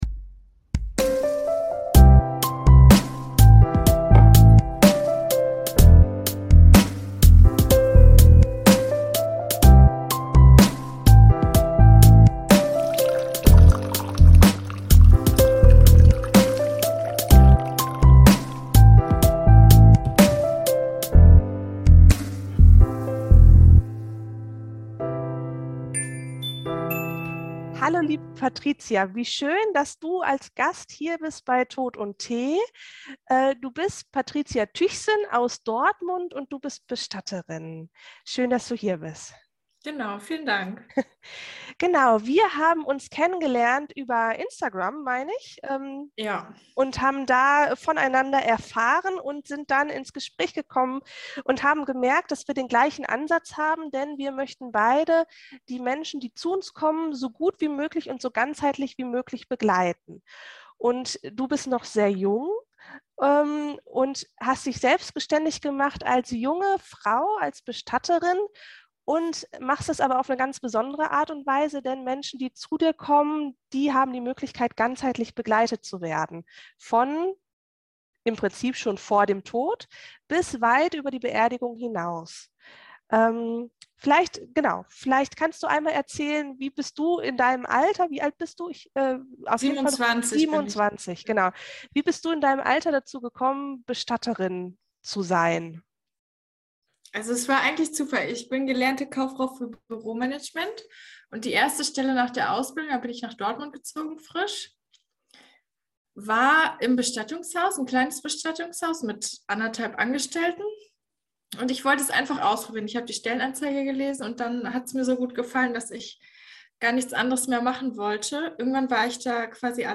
Im Gespräch mit einer Bestatterin, die es ein bisschen anders macht